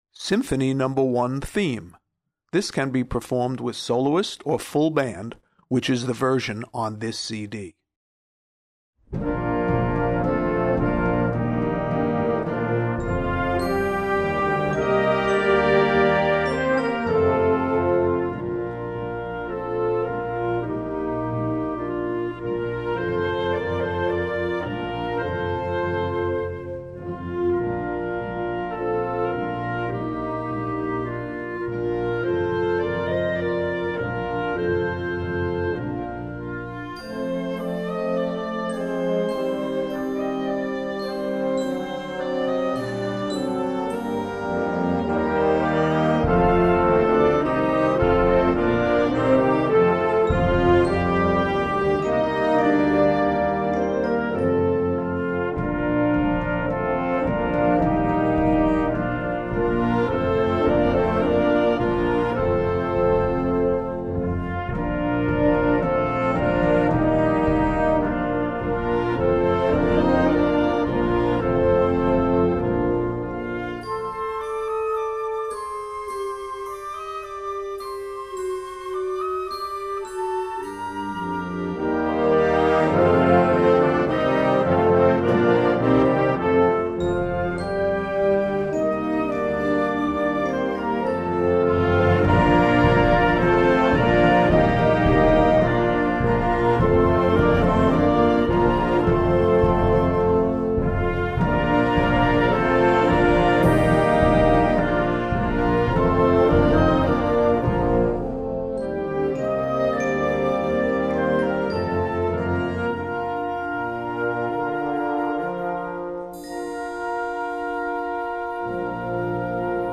Voicing: Instrument Solo w/ Band